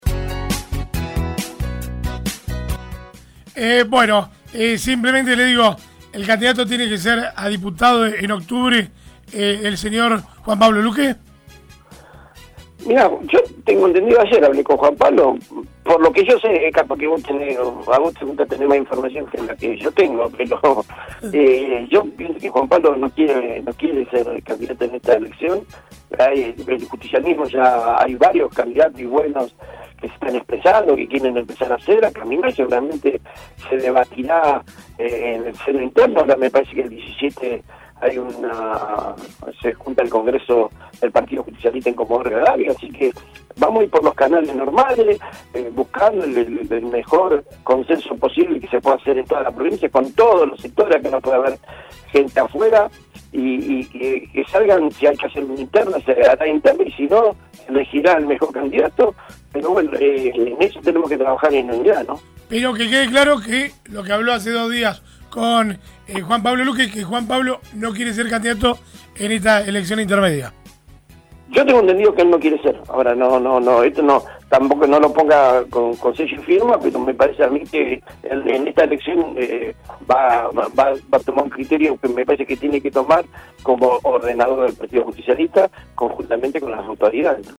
Así lo manifestó en los micrófonos de RADIOVISIÓN el senador nacional, Carlos Linares. Dijo que Juan Pablo Luque no tiene interés en ser candidato en la siguiente vuelta electoral: